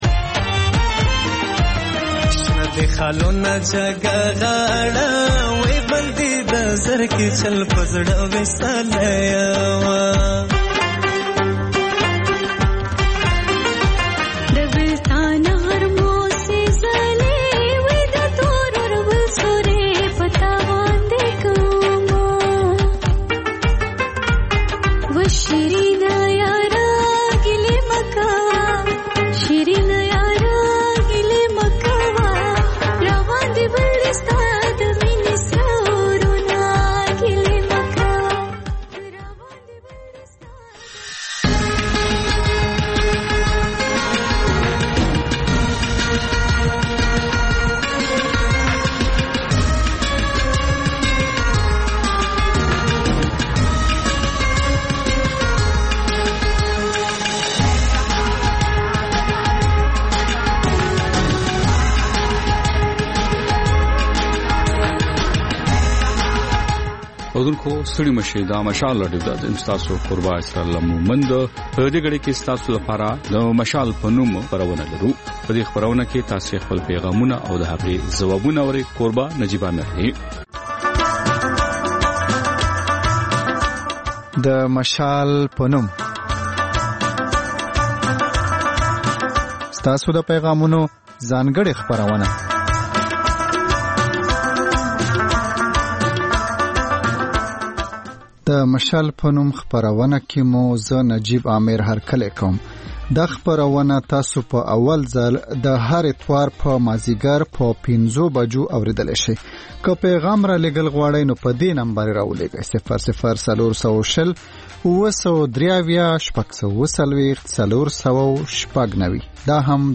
د خپرونې پیل له خبرونو کېږي، ورسره اوونیزه خپرونه/خپرونې هم خپرېږي.